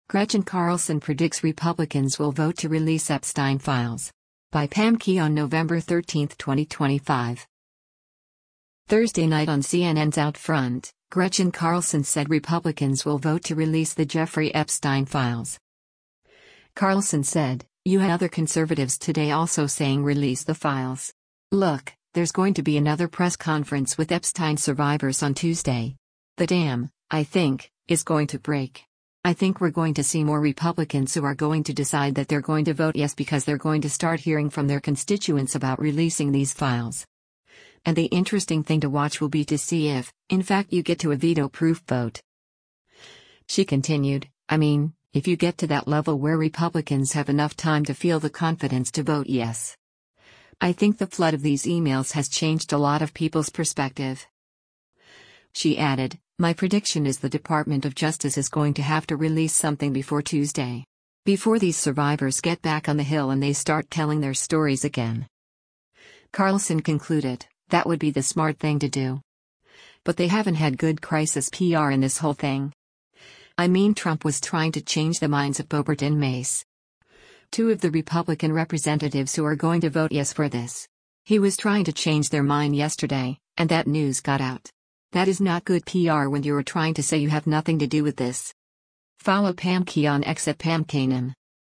Thursday night on CNN’s “OutFront,” Gretchen Carlson said Republicans will vote to release the Jeffrey Epstein files.